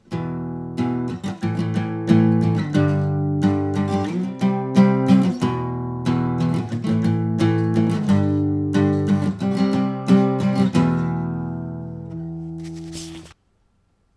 Rütmiharjutused (strum patterns)
20. Tüüpjärgnevus, a la “Smells Like Teen Spirit”: